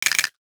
NOTIFICATION_Rattle_03_mono.wav